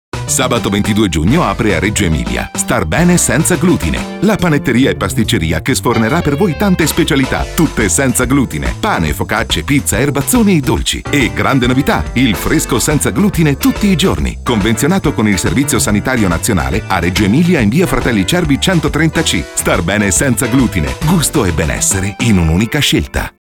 Spot radiofonico inaugurazione
starbenesenzaglutine_radio.mp3